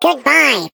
Sfx_tool_spypenguin_vo_selfdestruct_05.ogg